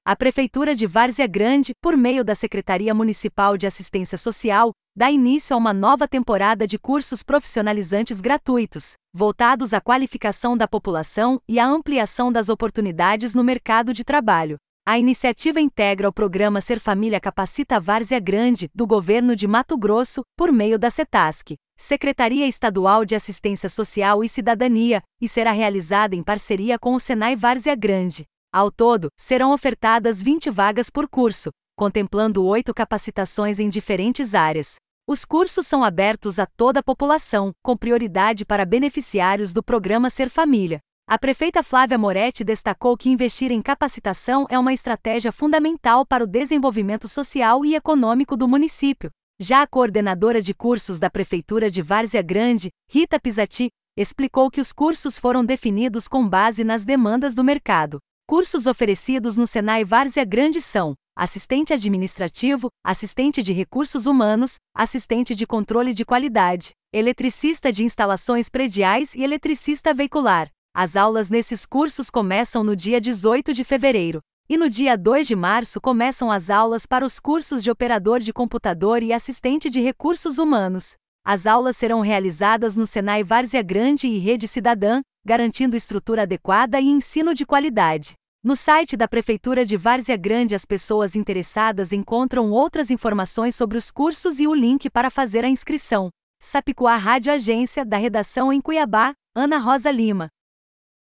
Boletins de MT 09 fev, 2026